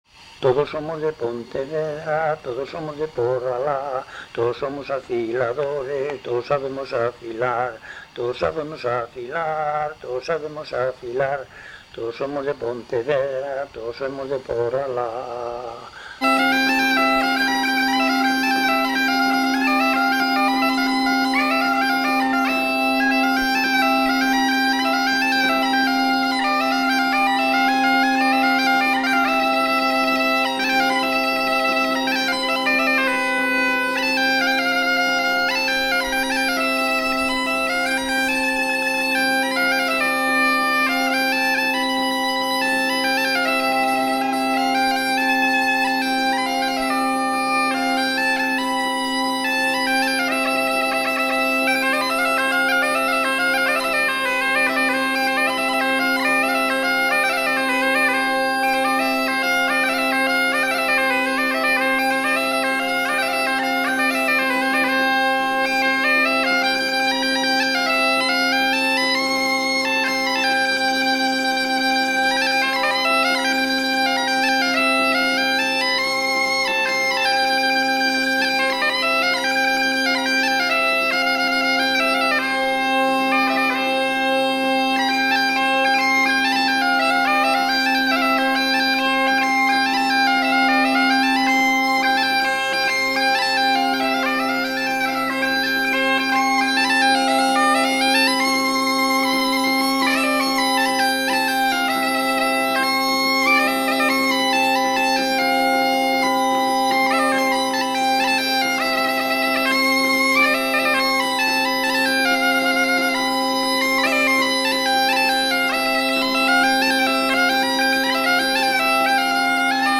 Escoitaremos un pasacalles
Este popurri de pasodobre e muiñeira